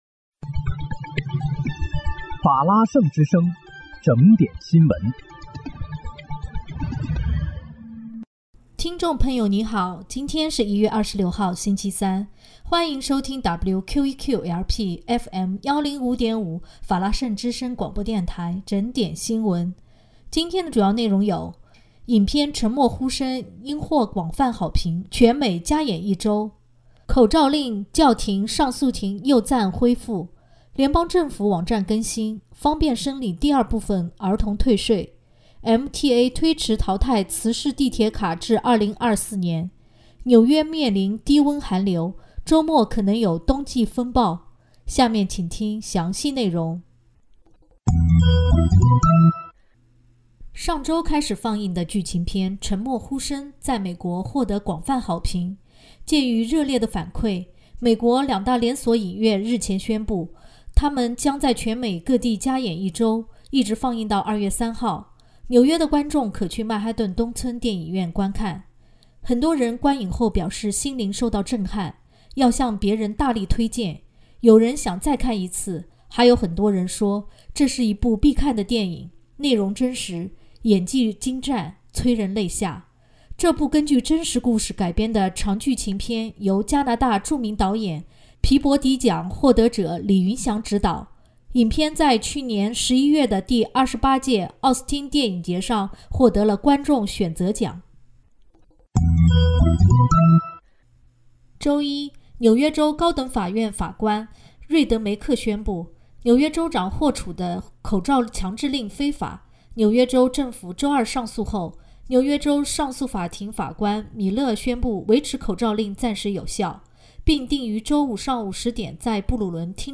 1月26日（星期三）纽约整点新闻